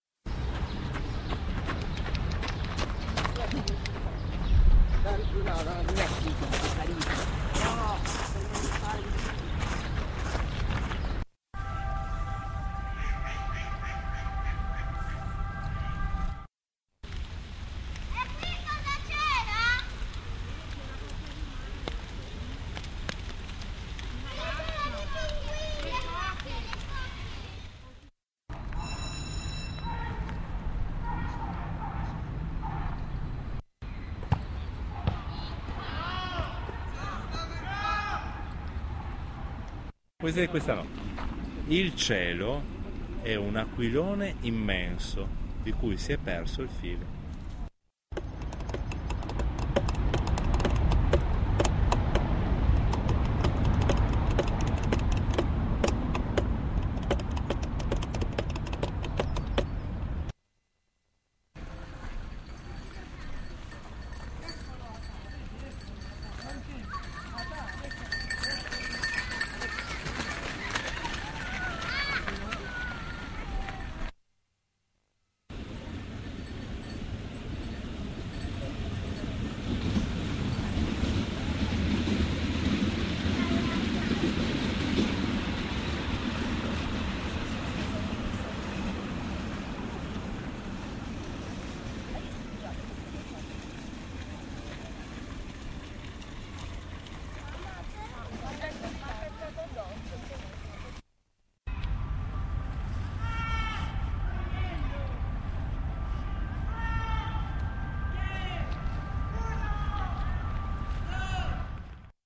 frammenti-d-ascolto-giardini-montanelli-mi.mp3